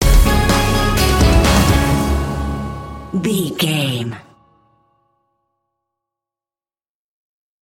Epic / Action
In-crescendo
Phrygian
C#
drums
electric guitar
bass guitar
hard rock
aggressive
energetic
intense
nu metal
alternative metal